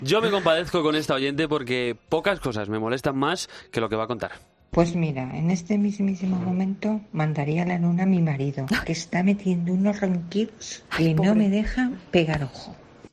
Esta oyente nos cuenta el motivo por el que enviaría a su marido a la luna